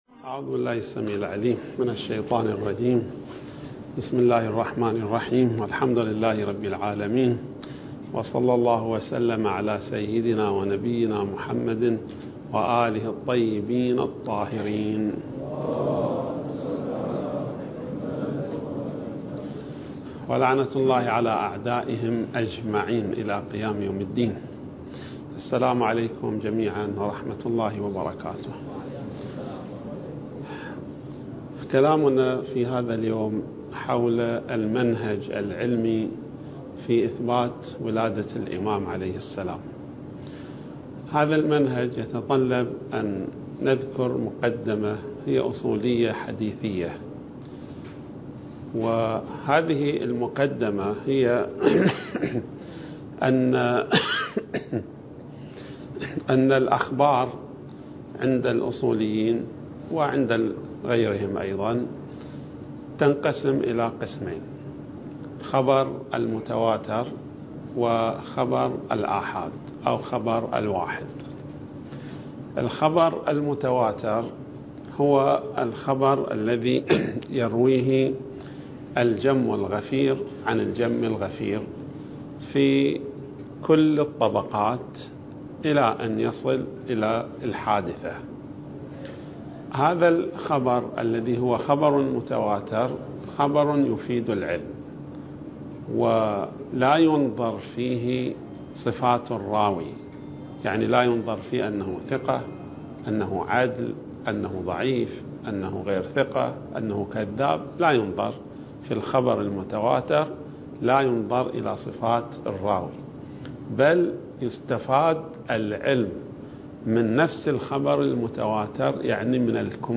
المكان: النجف الأشرف
(المحاضرة الرابعة والعشرون)